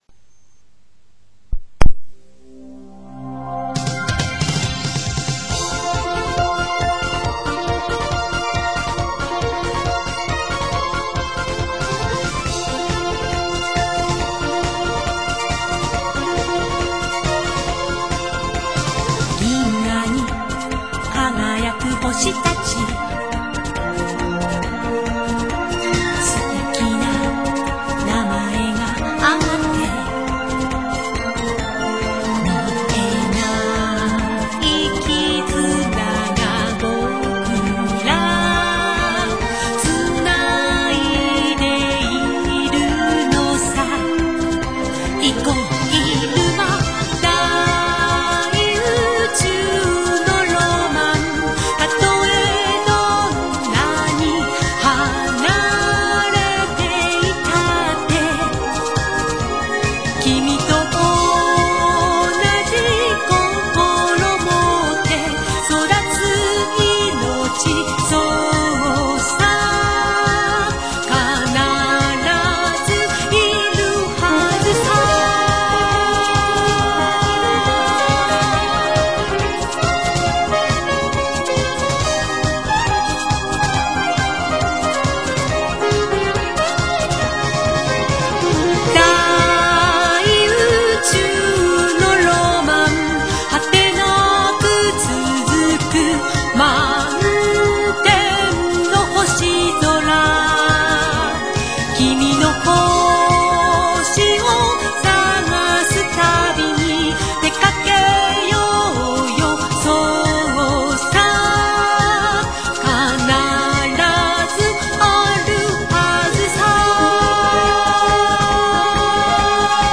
リズミカルなテンポで宇宙の雄大さを歌い上げています。